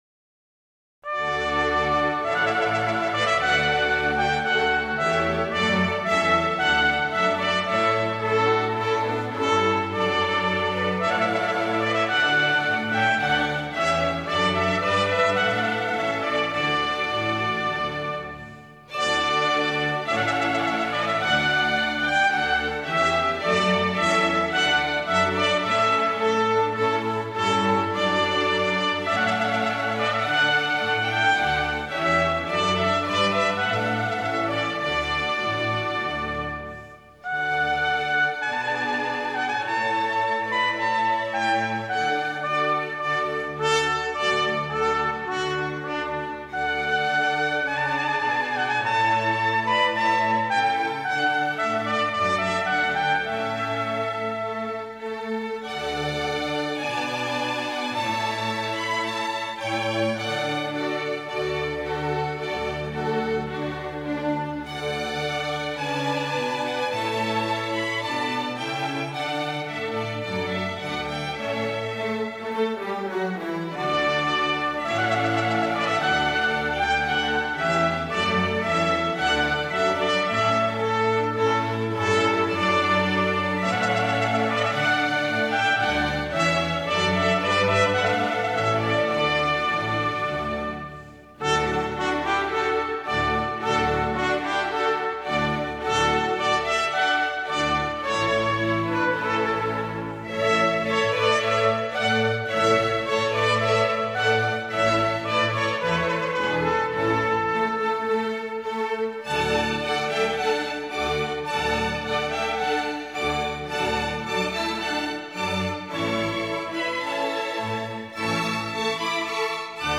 05-trumpet-voluntary.m4a